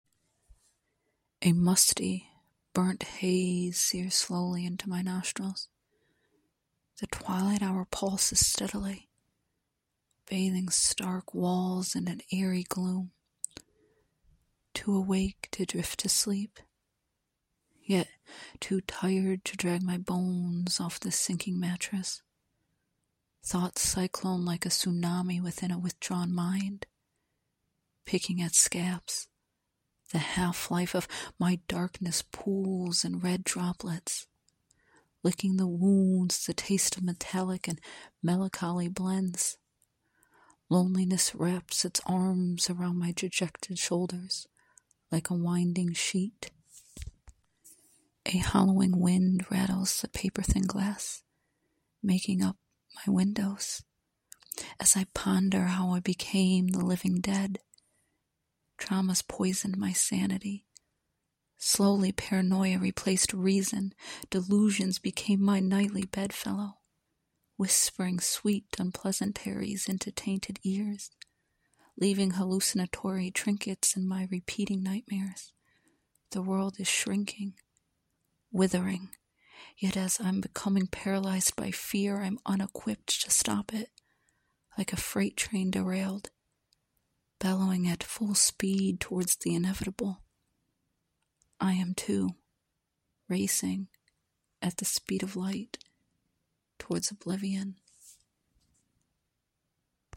what a pleasant voice ~ both eerie and charming ~
A very pleasant voice with just the right intonation for this poem... which btw, is excellent!
Oh so powerful voice.
I could listen to your soft, soothing voice for hours.
Your voice lives each tragic line.